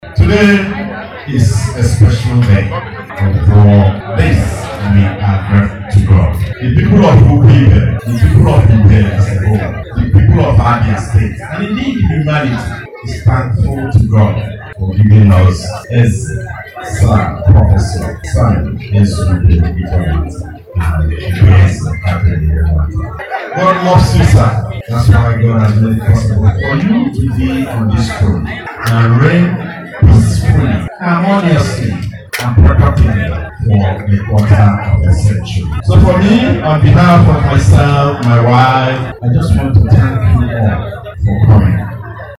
In his remarks, the son-In-Law to Eze Ifenwata, and the Member representing South East on the Board of the North East Development Commission Rep Sam Onuigbo appreciated all the guests that graced the occasion as he noted that the people of Ibere, Abia and indeed humanity have in one way or the other been impacted positively by the Monarch.